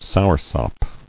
[sour·sop]